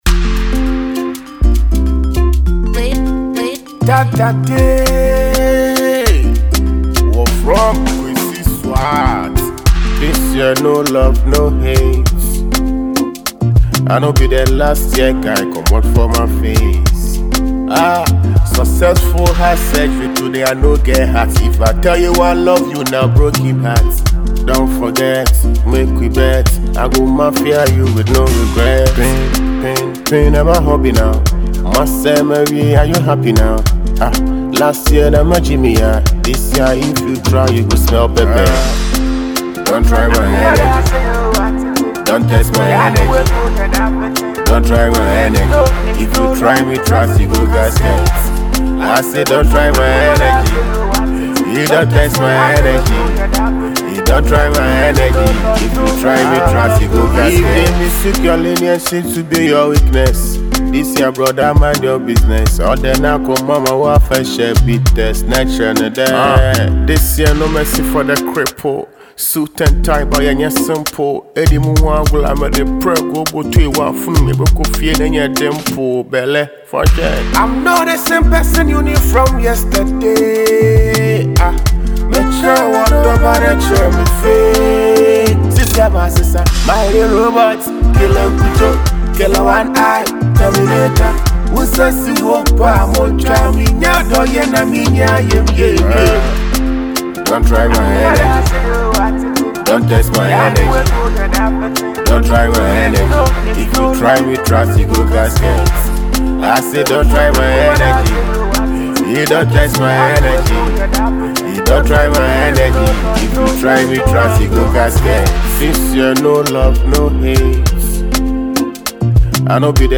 Talented Ghanaian Highlife artiste